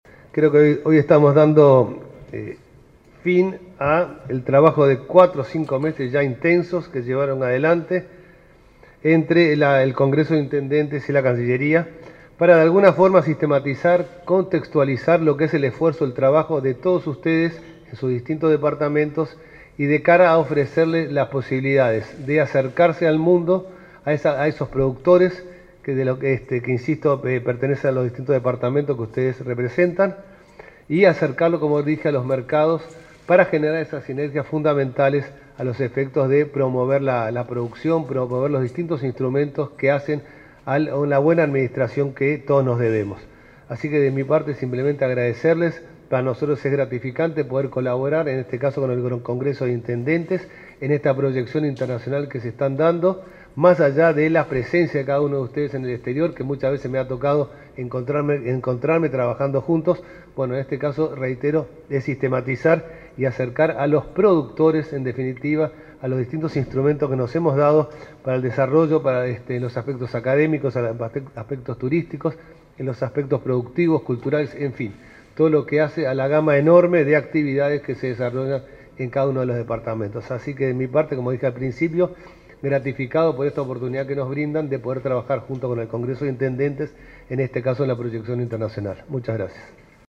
Palabras del canciller Francisco Bustillo
El Congreso de Intendentes recibió este jueves 17 al ministro de Relaciones Exteriores, Francisco Bustillo, con quien firmó un convenio marco de